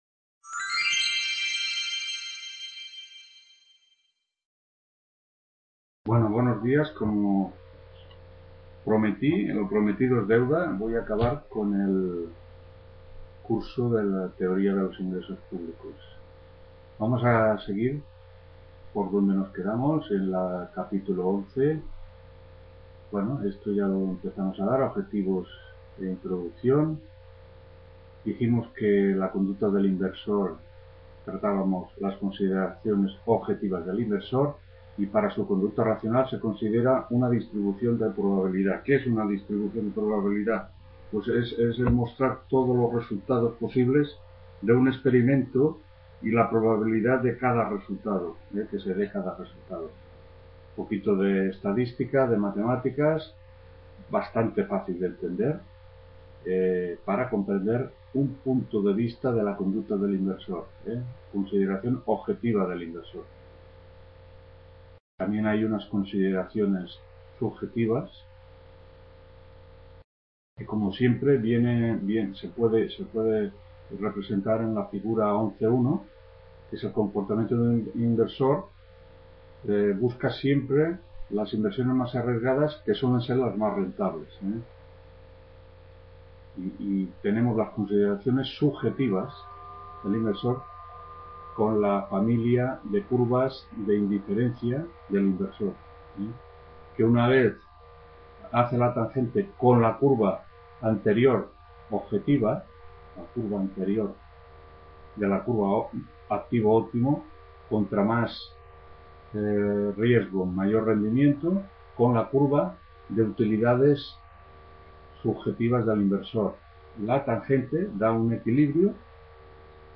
TUTORÍA AVIP